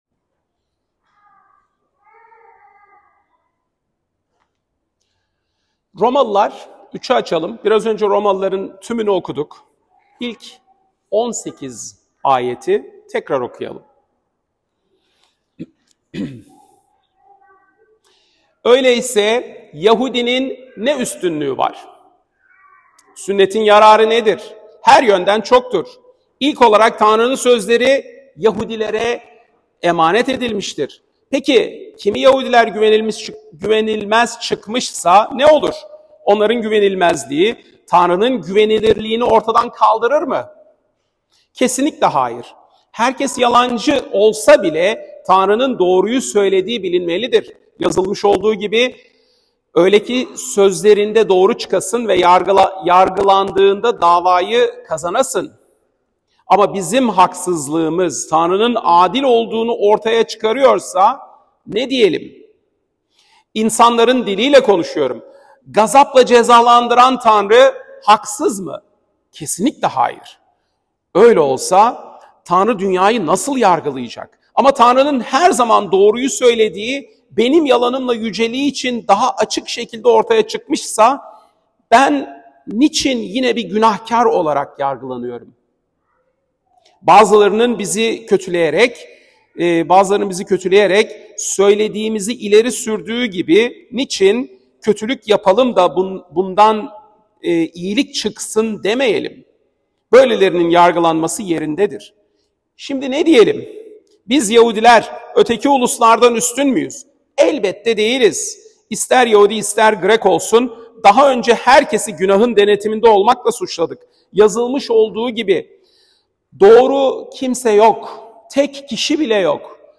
Pazar, 2 Şubat 2025 | Romalılar Vaaz Serisi 2025, Vaazlar